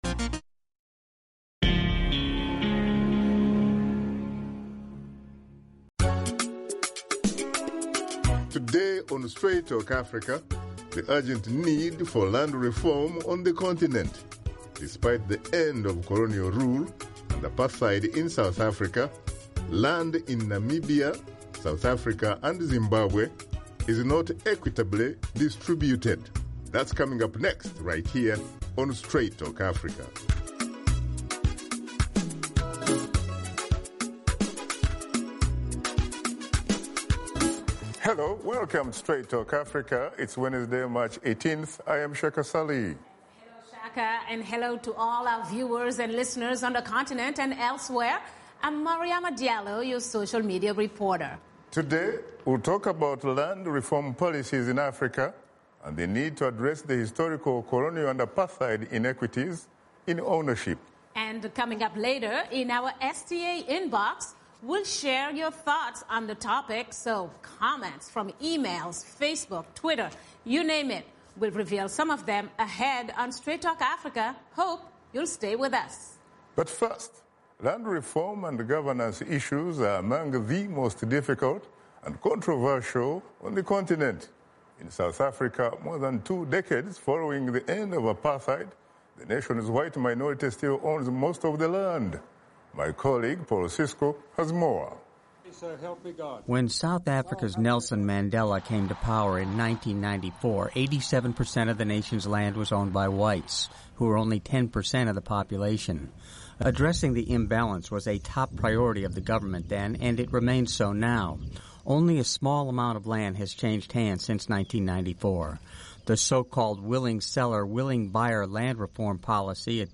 via Phone: Livingston, Zambia